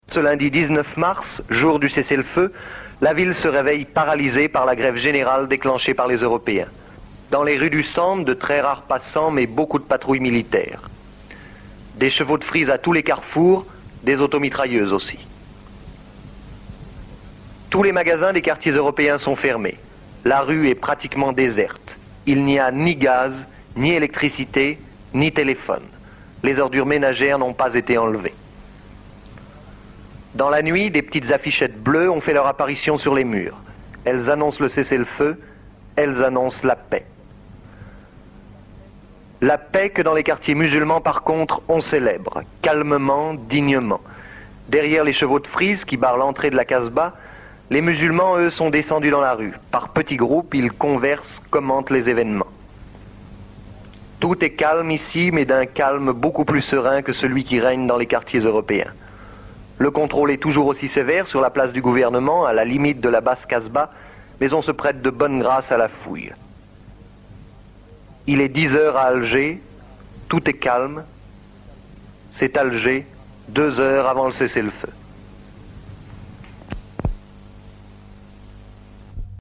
Revivre le 19 Mars 1962 à Alger : entre la désillusion des colons et la victoire des Algériens (archives sonores) | Radio Algérienne
archives sonores 19 mars 1962